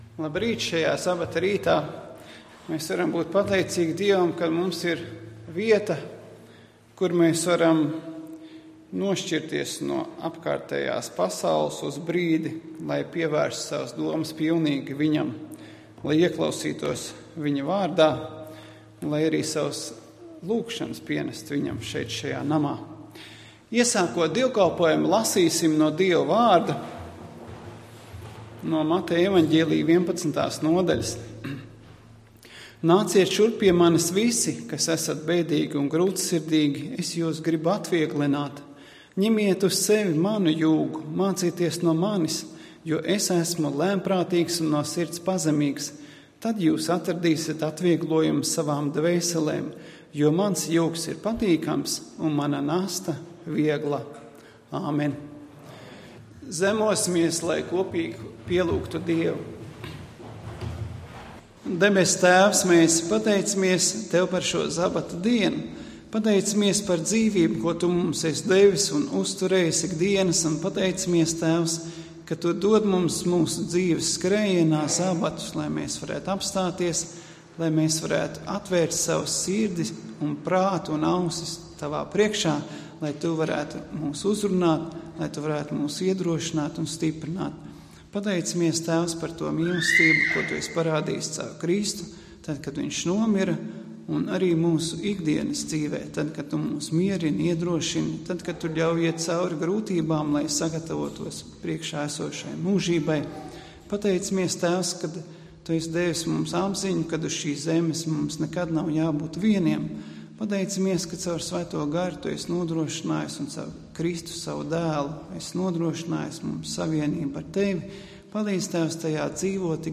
Dievkalpojums 31.05.2014: Klausīties
Svētrunas